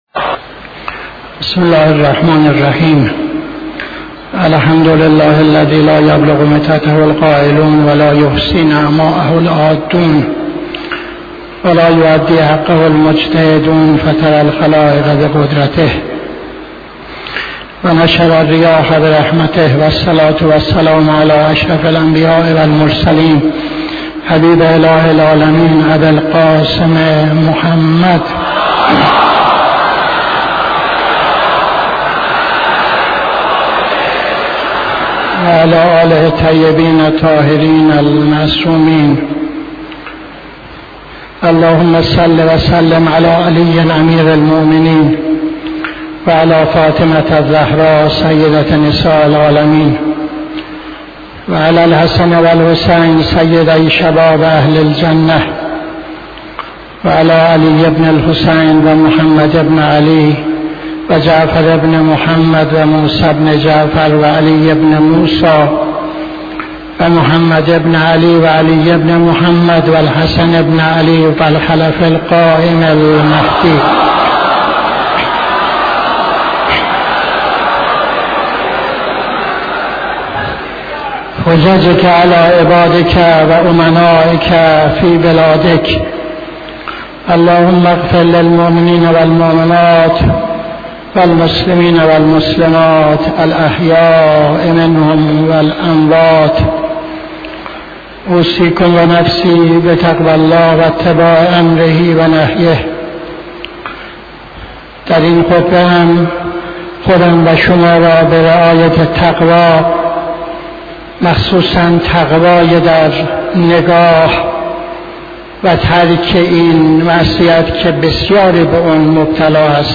خطبه دوم نماز جمعه 06-03-79